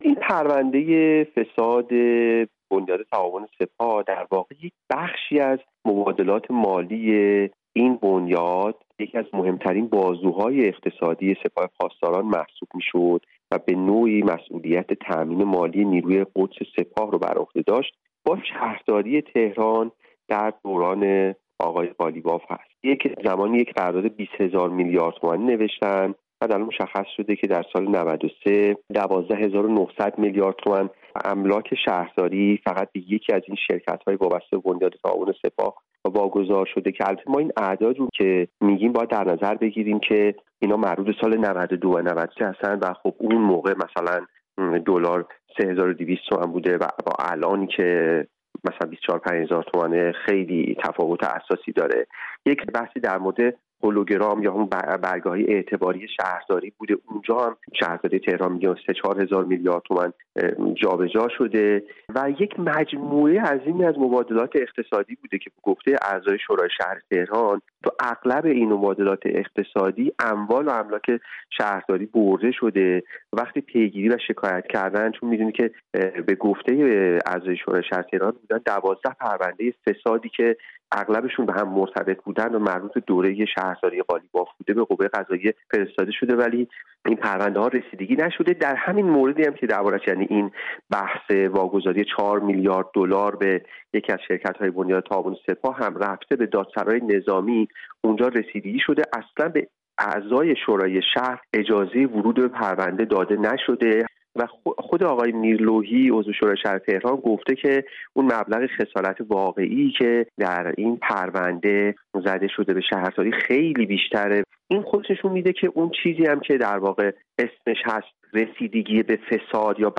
گفتگو کرده